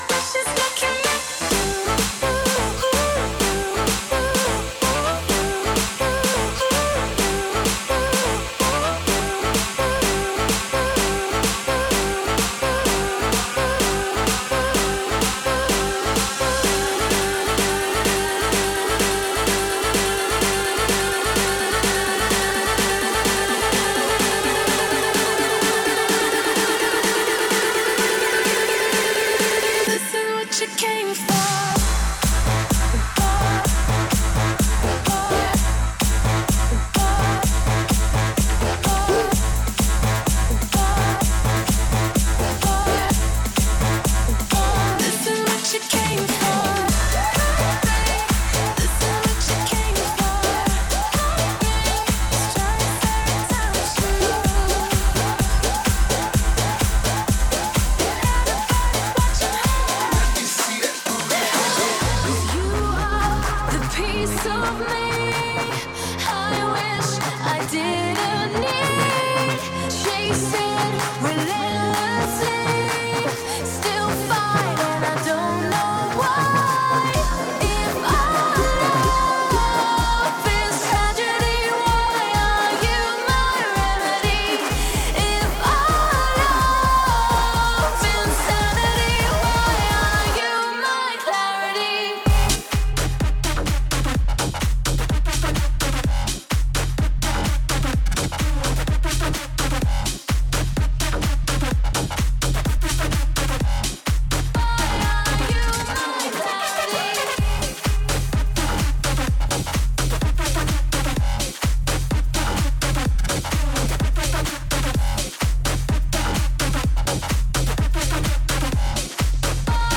A live EDM and dance mix